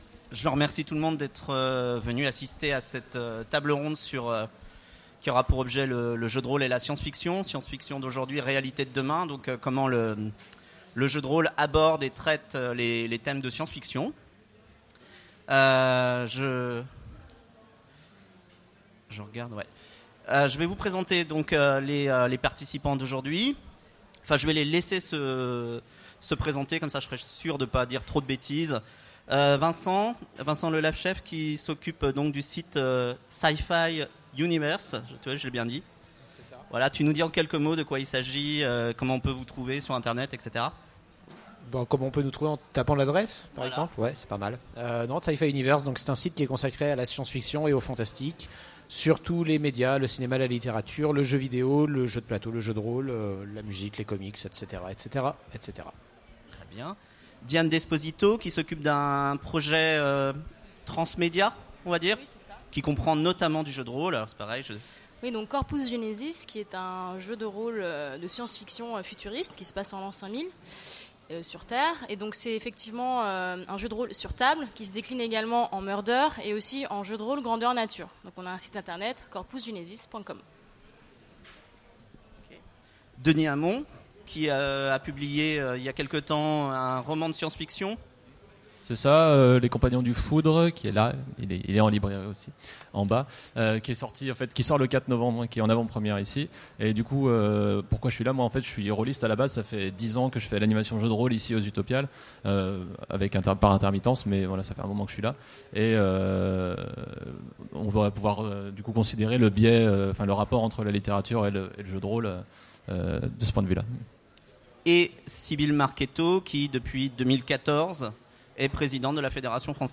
Utopiales 2015 : Conférence Science-fiction d’aujourd’hui, réalités de demain ?